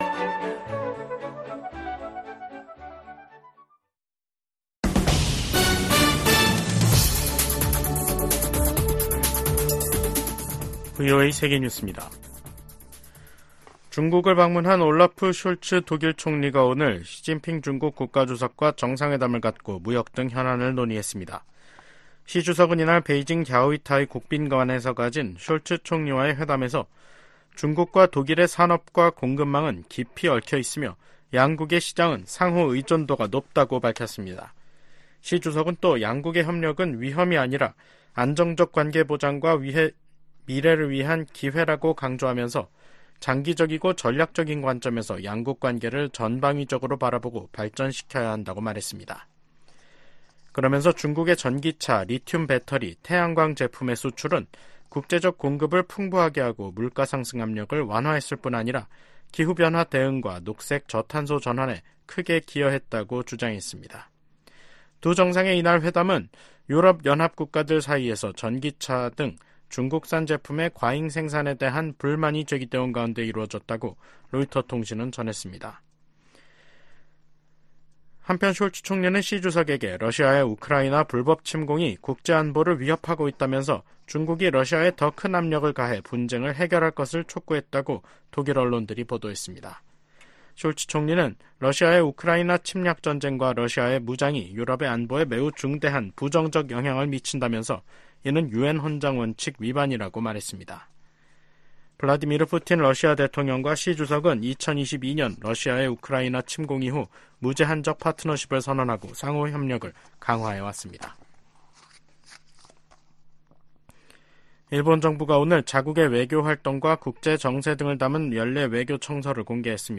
VOA 한국어 간판 뉴스 프로그램 '뉴스 투데이', 2024년 4월 16일 2부 방송입니다. 미국은 이스라엘에 대한 공격을 사전에 통보했다는 이란의 주장은 거짓이라고 반박했습니다. 이스라엘을 공격한 이란 미사일 잔해에서 북한 기술의 흔적이 발견돼도 놀랍지 않을 것이라고 전 국무부 군축∙국제안보 차관이 말했습니다.